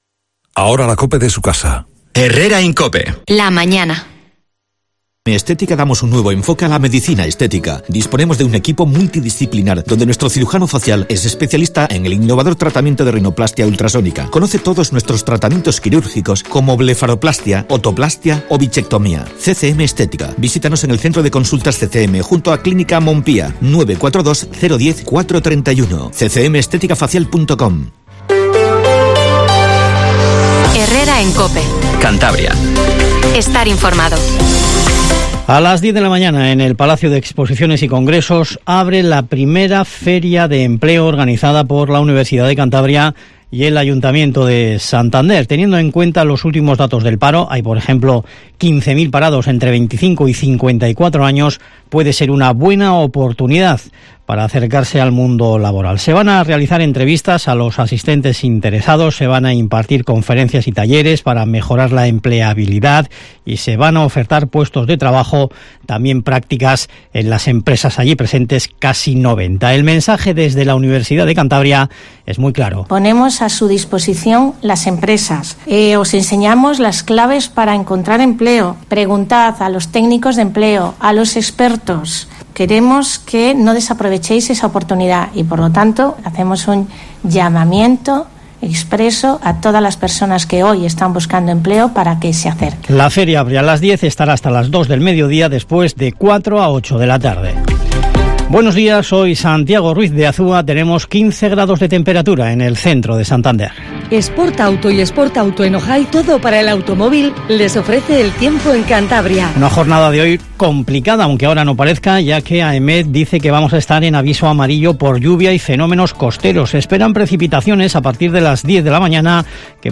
Informativo HERRERA en COPE CANTABRIA 08:24